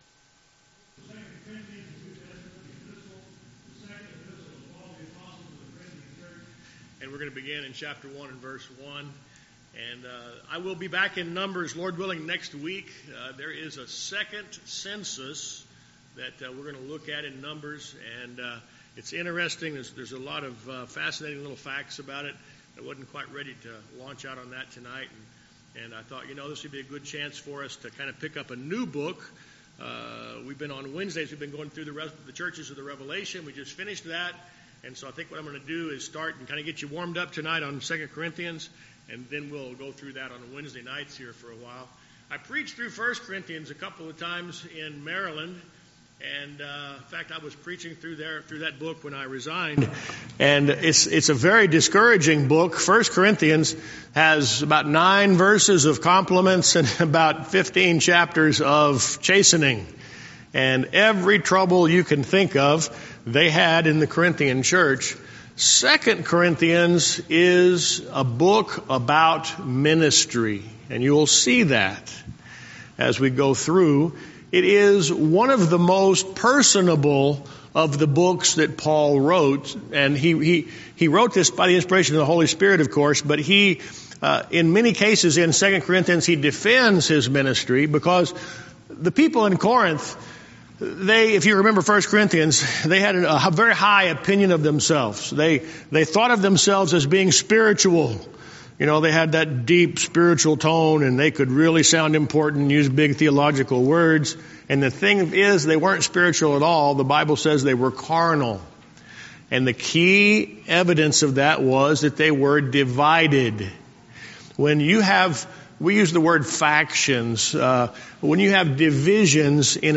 Series: Guest Speaker